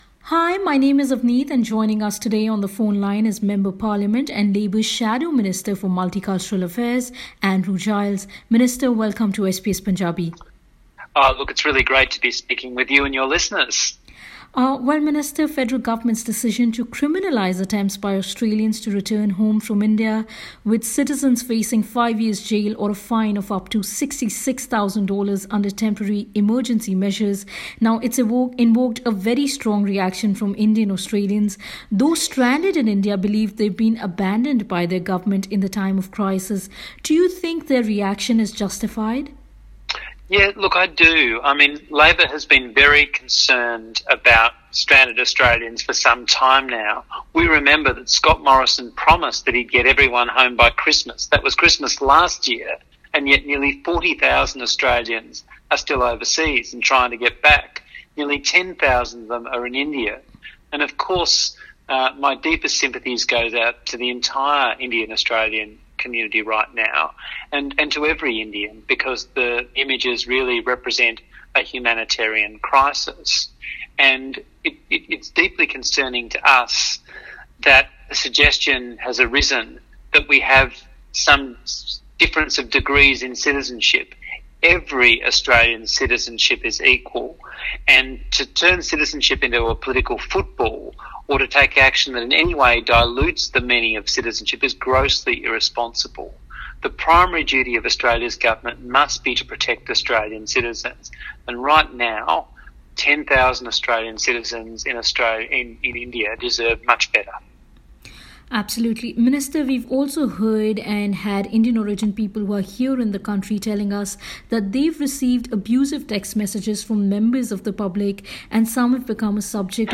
In an interview with SBS Punjabi, Minister Giles said the government should stop blaming people from one country and instead put all its efforts towards bringing its citizens home.